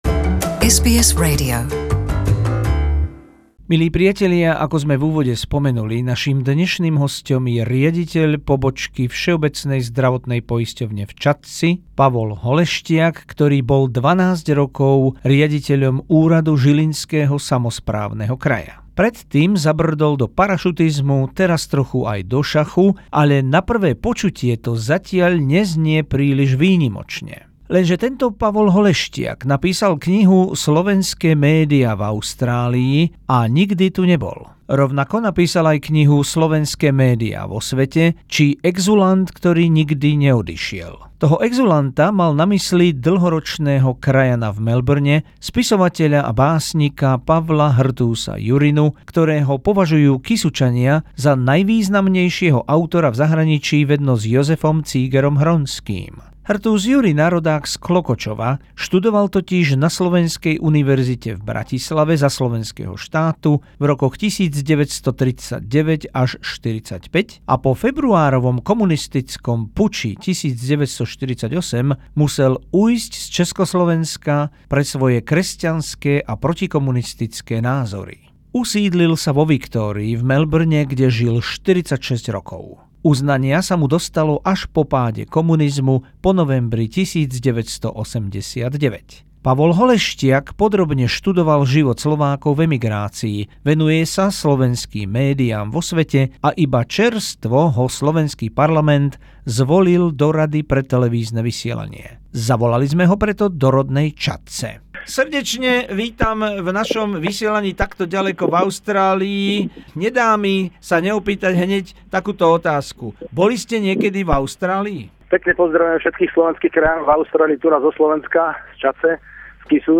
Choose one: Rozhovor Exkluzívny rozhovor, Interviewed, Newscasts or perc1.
Rozhovor Exkluzívny rozhovor